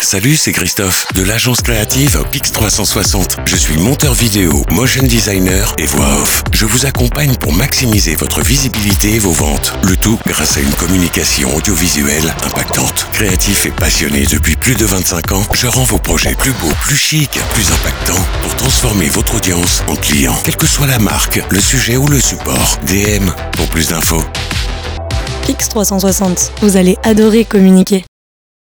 PRéSENTATION rapide